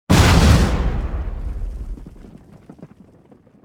wav / ships / combat / youhit4.wav
youhit4.wav